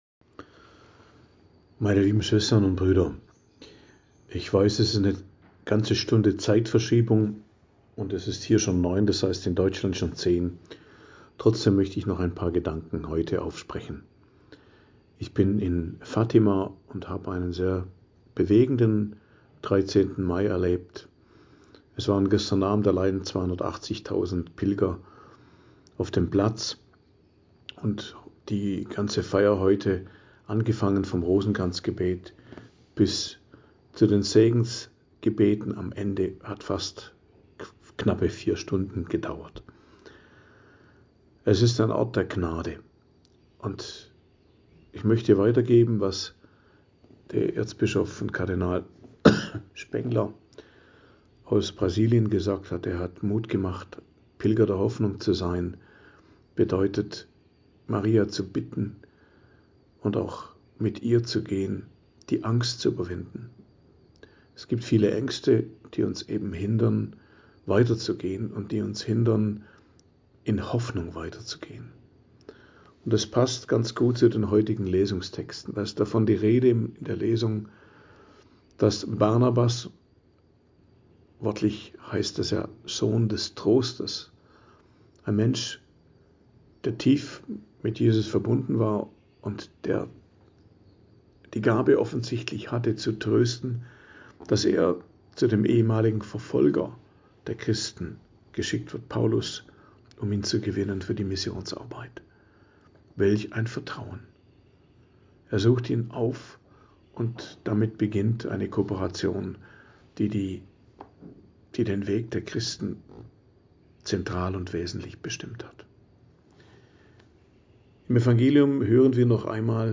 Predigt am Dienstag der 4. Osterwoche, 13.05.2025